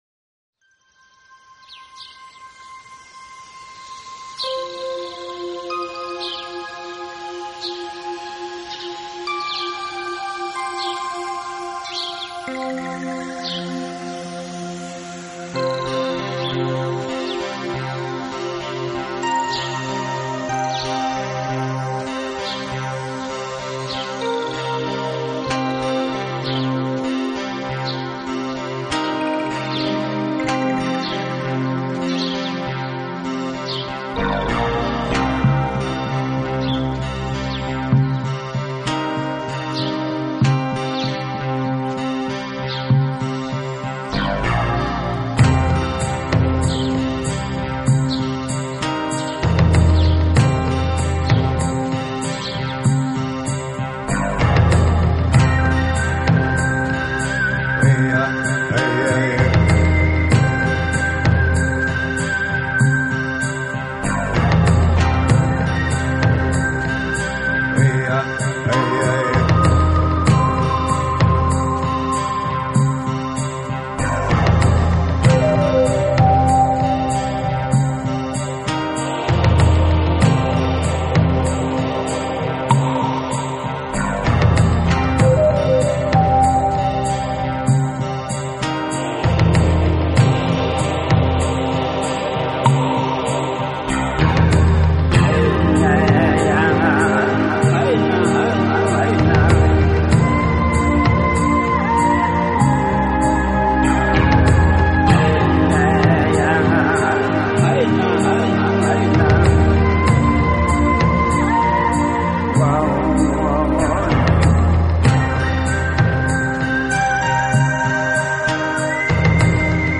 【轻音乐专辑】
年代组成的现代摇滚风格的乐队